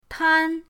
tan1.mp3